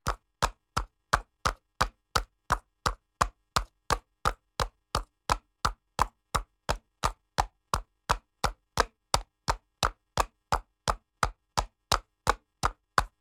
horse_walk_concrete.ogg